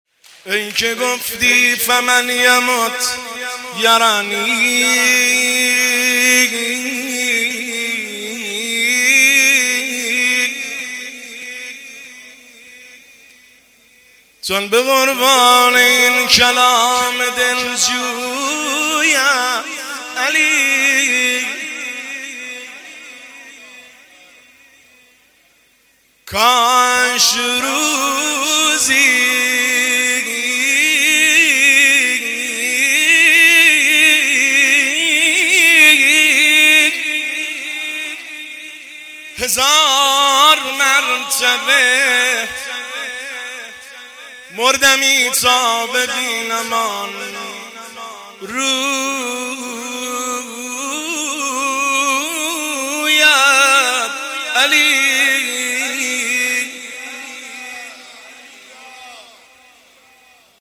زمزمه شب 20 رمضان المبارک 1403
هیئت بین الحرمین طهران